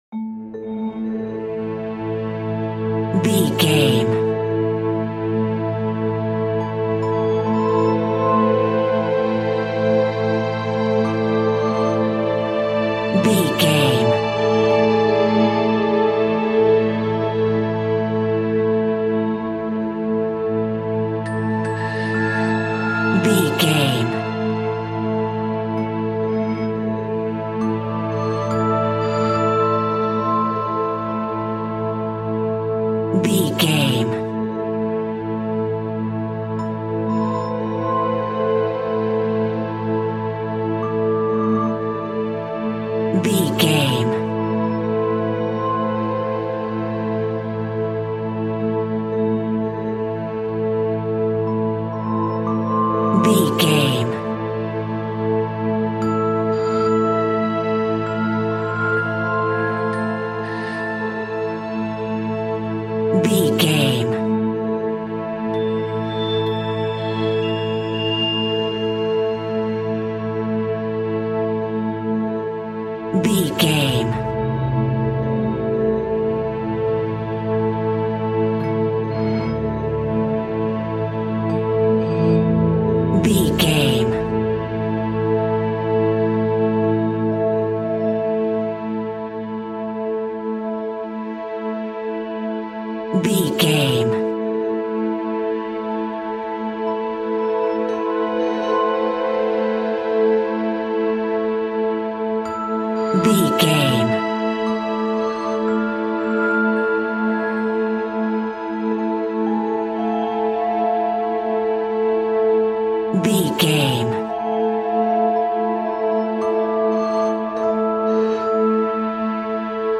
Scary Soundscape.
Aeolian/Minor
Slow
scary
tension
ominous
dark
eerie
strings
synthesiser
percussion
electric piano
ambience
pads